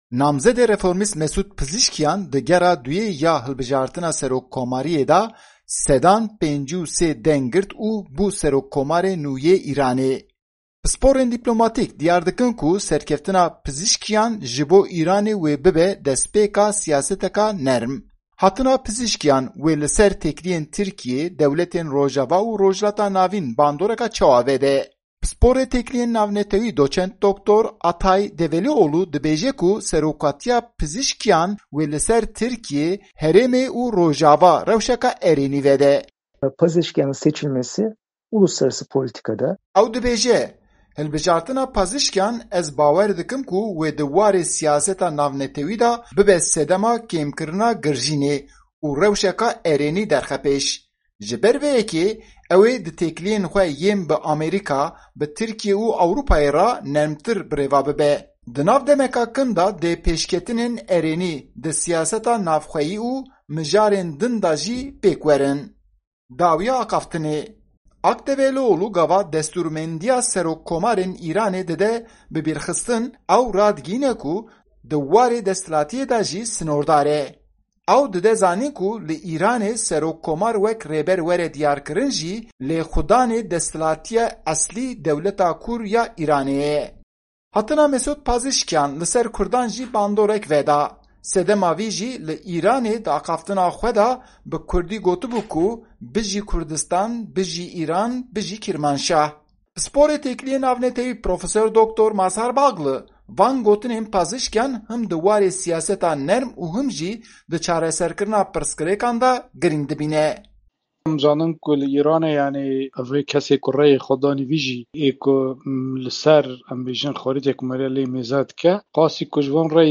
Raporta Enqerê - Şirove Derabrê Serokomarê Nû yê Îranê.mp3